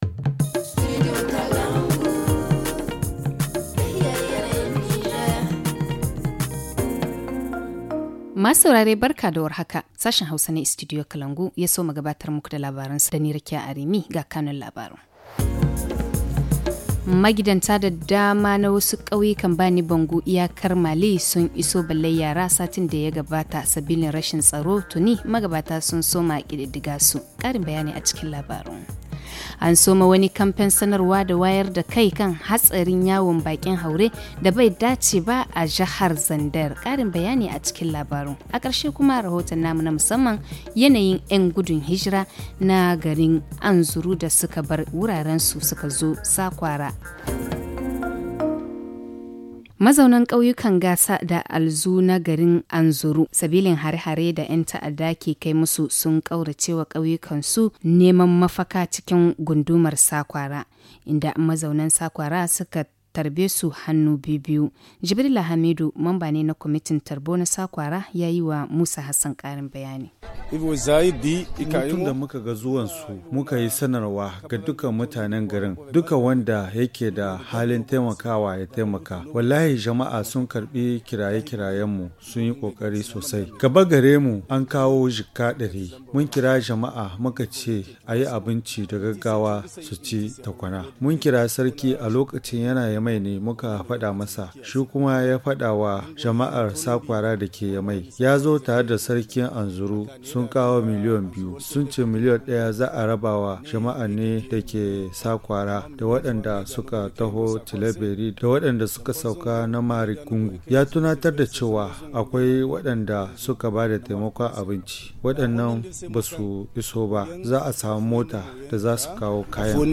Le journal du 19 mai 2021 - Studio Kalangou - Au rythme du Niger